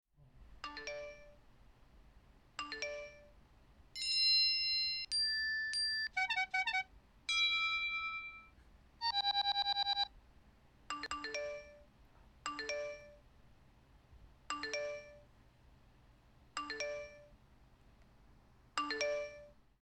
Sound Effect
Phone Alerts And Rings (1)
Home > Sound Effect > Alarms
Phone_Alerts_And_Rings (1).mp3